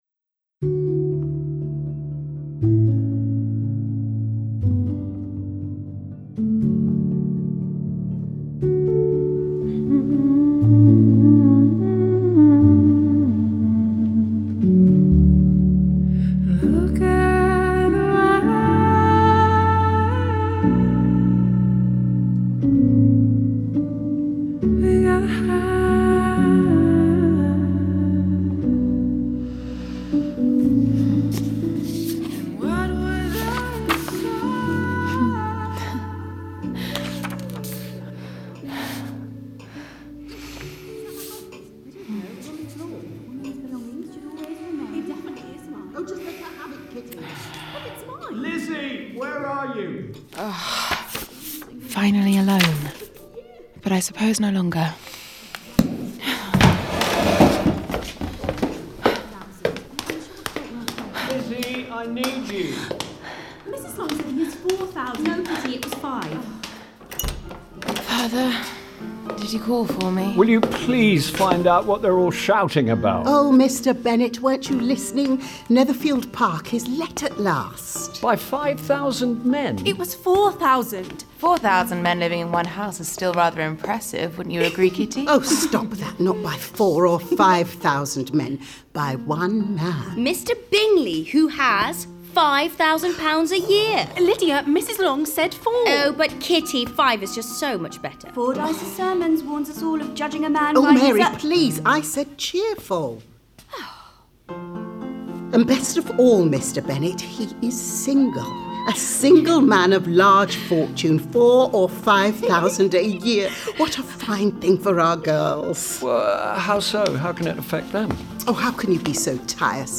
Listen to an excerpt of Audible’s new all-star performance of Jane Austen’s Pride and Prejudice!